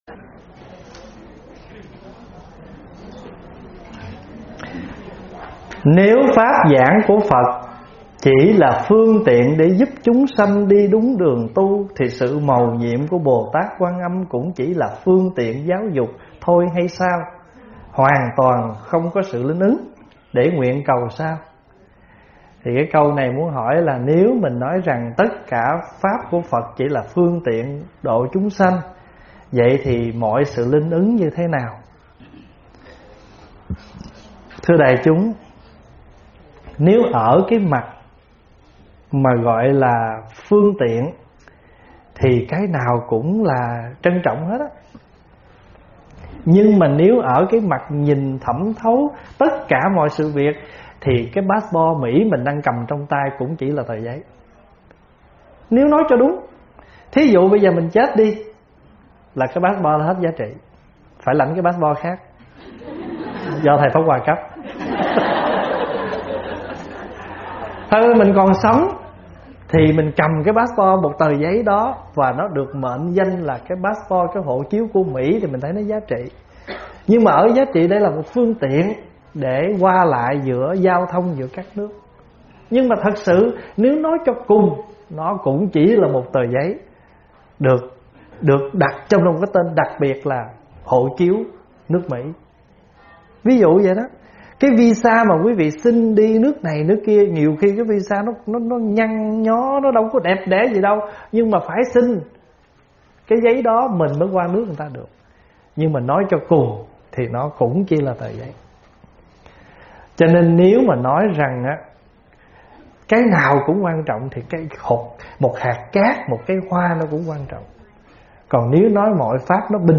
Vấn đáp phật pháp phải chỉ là phương tiện?